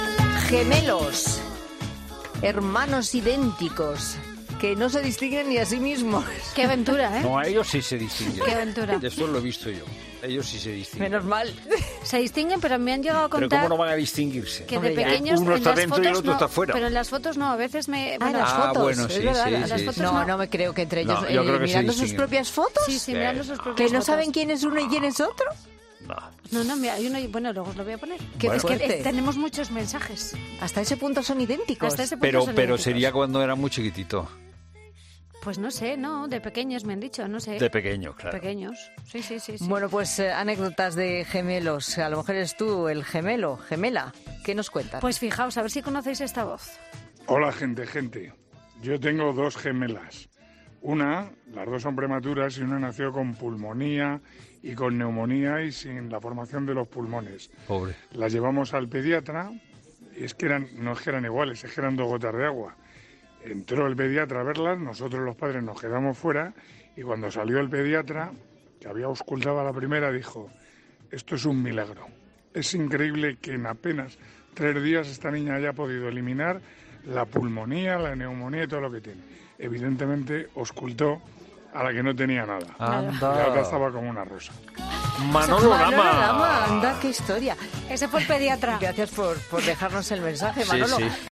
El periodista de 'Tiempo de Juego' ha intervenido este lunes en 'La Tarde' y ha contado una particular anécdota que vivió cuando sus dos hijas gemelas eran apenas recién nacidas
"Evidentemente, auscultó a la que no tenía nada, a la que estaba como una rosa", concluyó el periodista deportivo, poniendo así fin a su breve intervención a 'La Tarde' y despertando las risas de todos los participantes del programa.